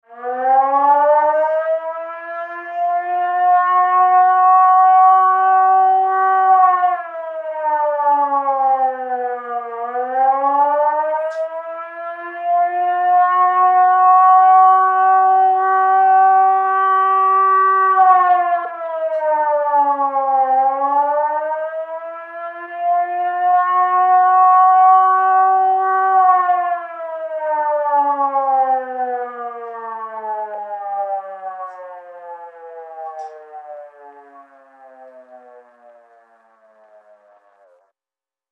Звуки сирены
Сирена компактного размера